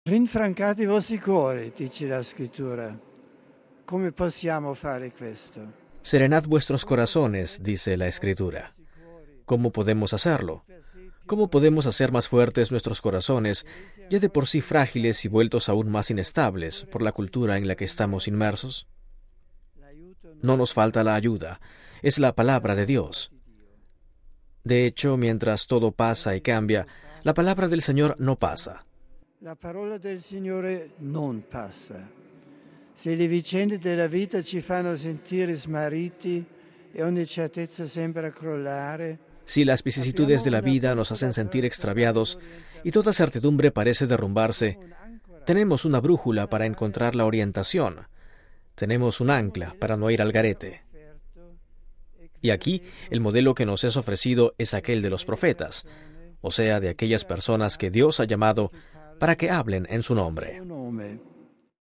Dom. 12 dic. (RV). - Como todos los domingos al mediodía, el Papa Benedicto XVI ha dirigido la oración mariana del Ángelus.